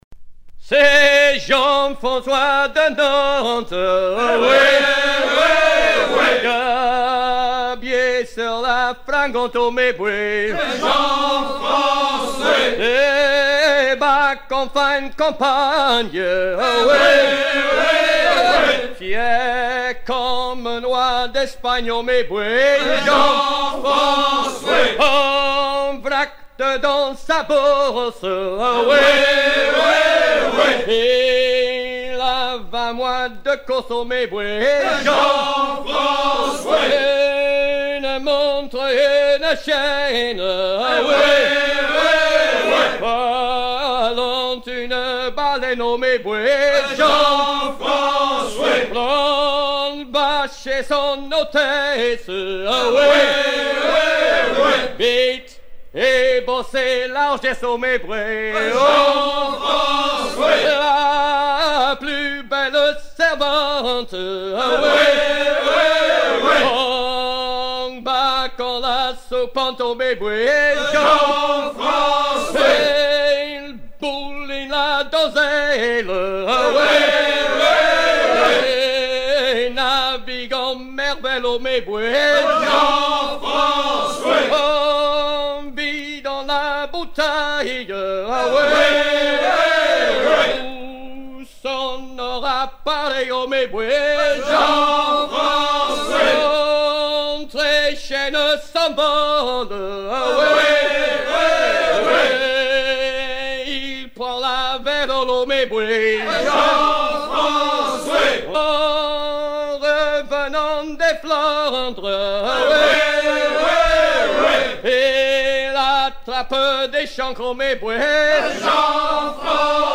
gestuel : à hisser à grands coups
Genre laisse